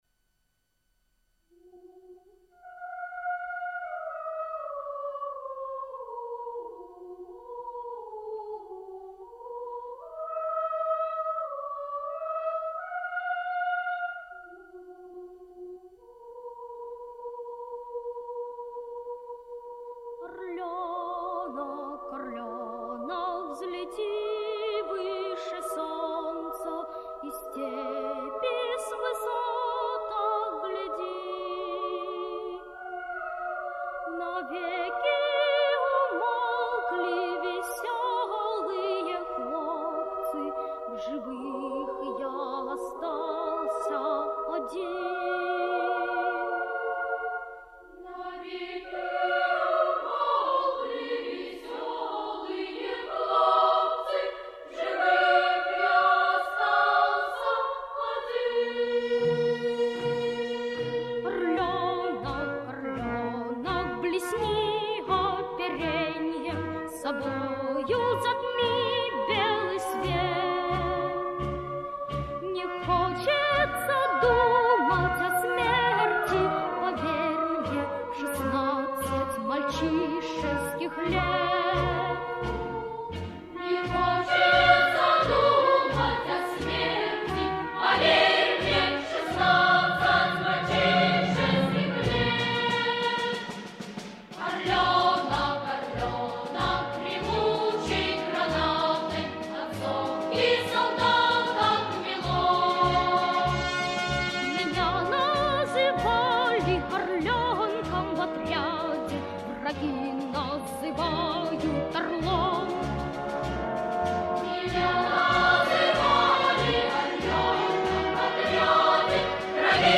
Явно недостающий вариант исполнения, с прекрасным солистом.